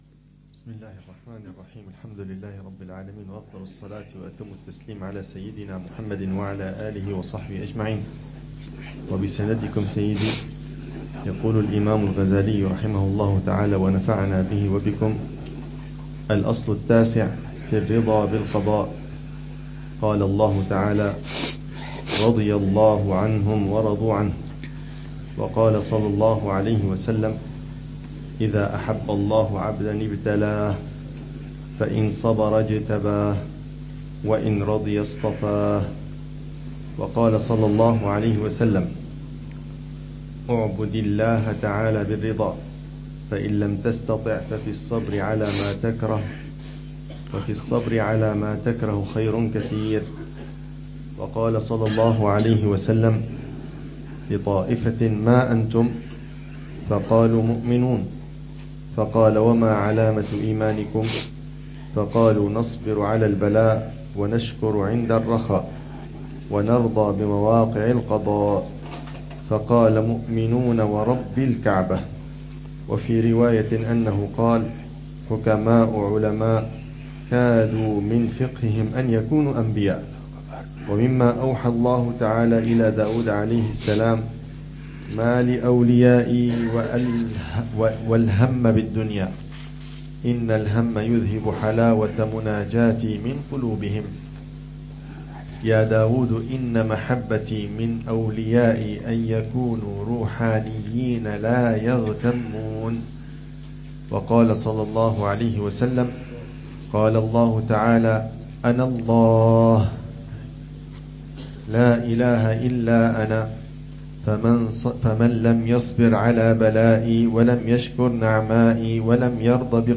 الدرس ال41 في شرح الأربعين في أصول الدين: شرح الأصل التاسع: الرضا بالقضاء، وشرح بداية الأصل العاشر: ذكر الموت (1)